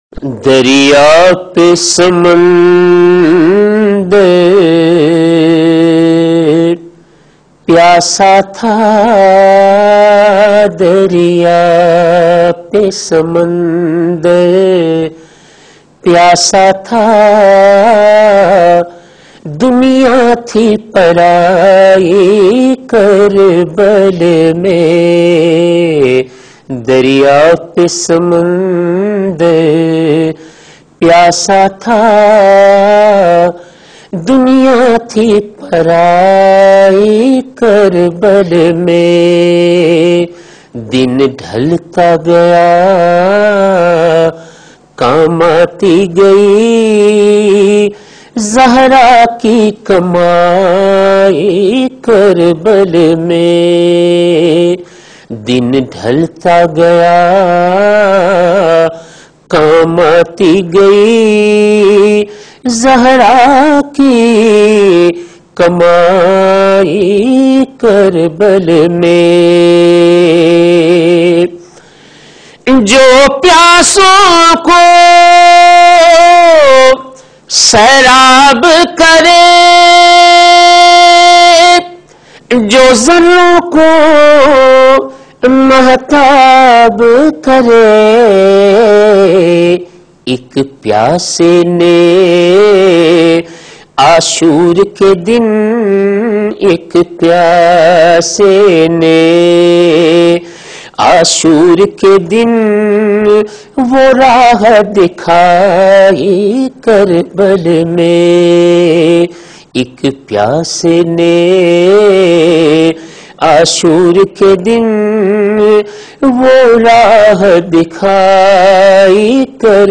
Known for his melodious voice and emotive delivery